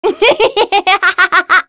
Sonia-vox-Happy3.wav